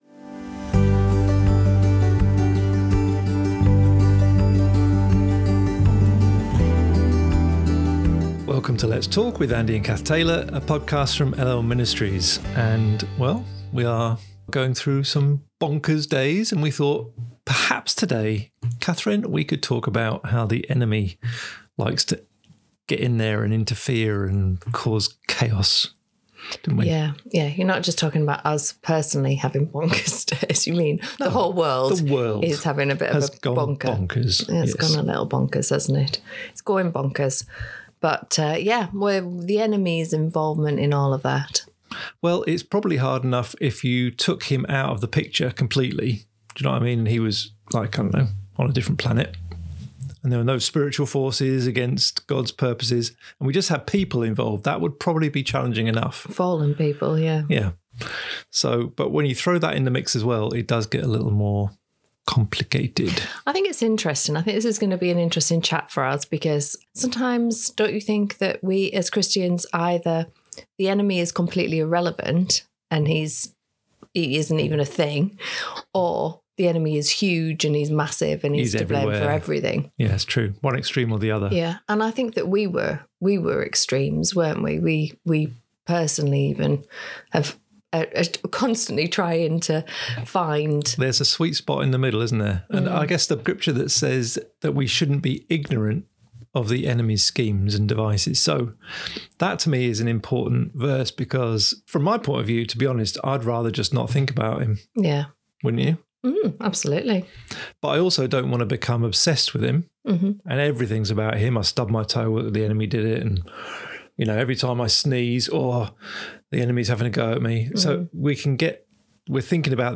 Play Rate Listened List Bookmark Get this podcast via API From The Podcast Join us as we discuss struggles we can all face in life and how God can bring real freedom and healing.